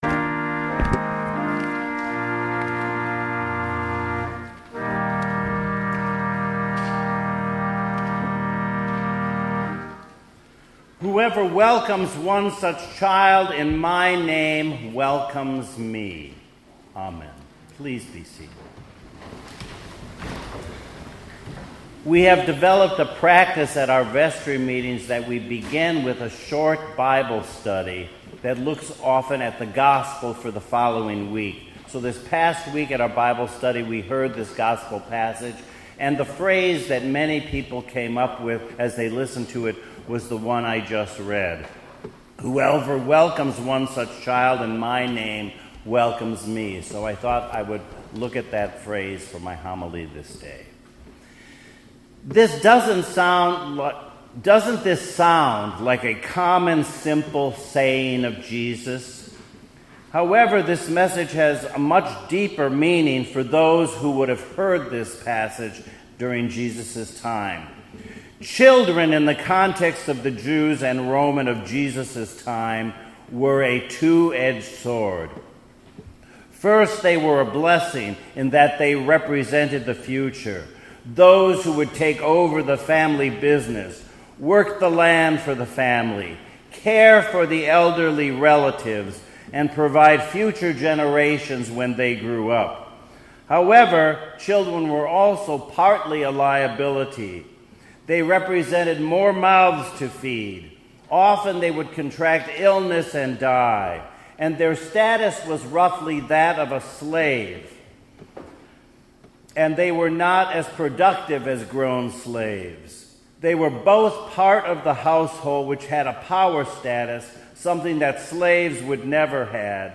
2018 Sunday Sermon